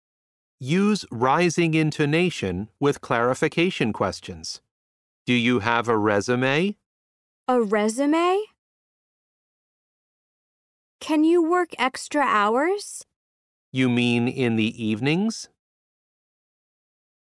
SO1- Unit 7- Lesson 4 (Intonation).mp3